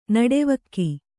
♪ naḍevakki